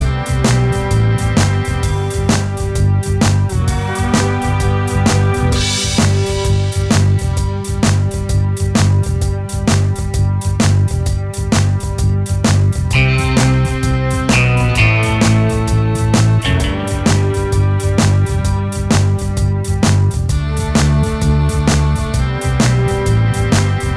Minus Lead Guitar Glam Rock 4:09 Buy £1.50